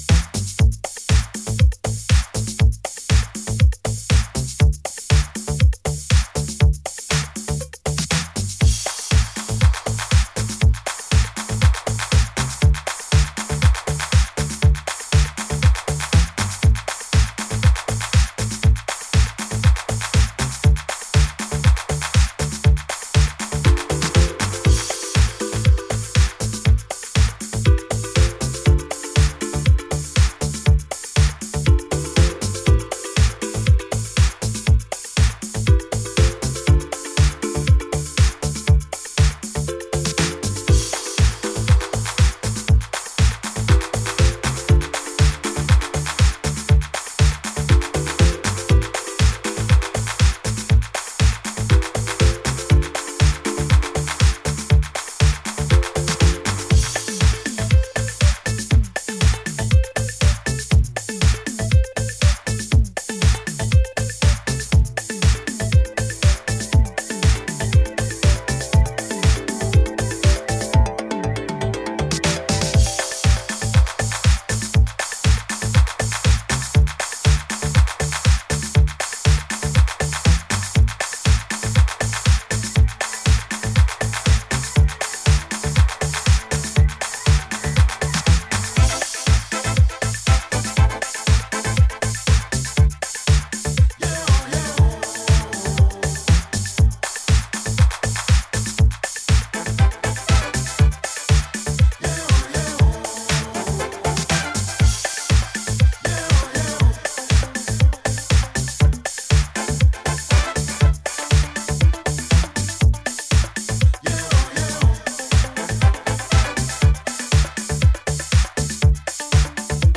ワールド・ミュージック的な雰囲気が最高のグルーヴィーなディスコ〜ハウス・チューン！